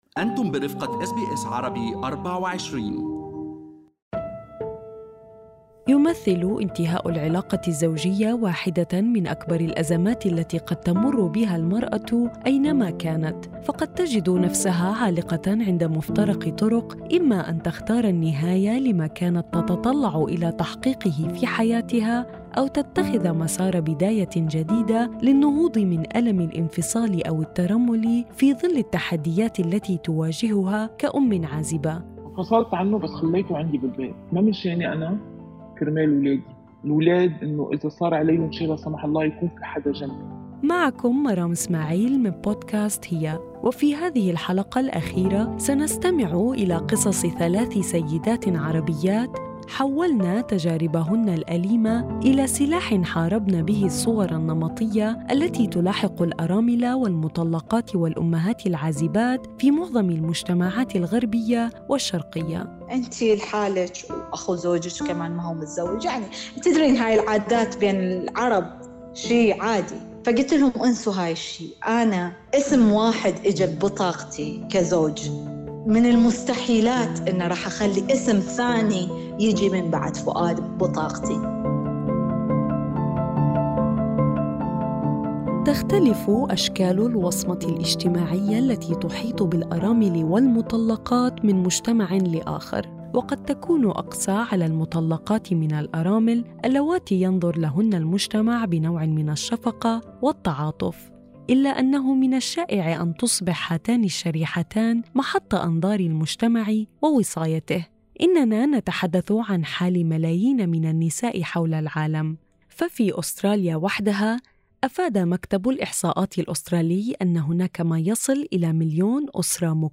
In the last episode of the 'She' podcast, three Arab women living in Australia discuss how they overcame their difficulties and broke free from societal stereotypes as widows, divorcees and single mothers in both Western and Eastern cultures. Despite their hardships, they found a way to heal and move forward.